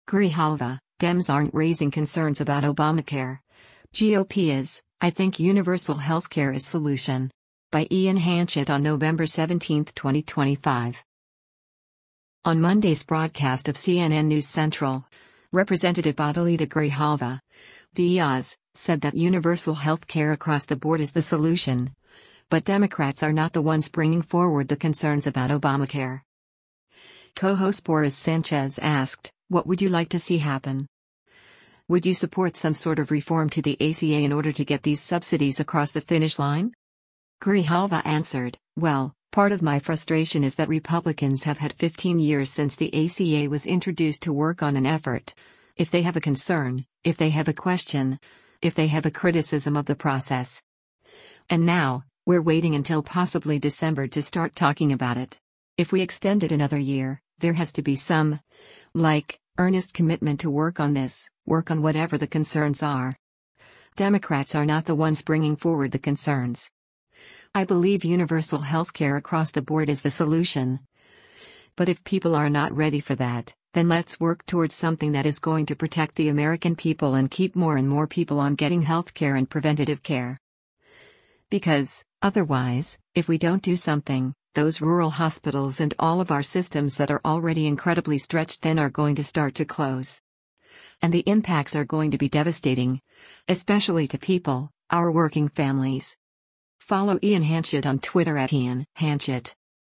On Monday’s broadcast of “CNN News Central,” Rep. Adelita Grijalva (D-AZ) said that “universal health care across the board is the solution,” but “Democrats are not the ones bringing forward the concerns” about Obamacare.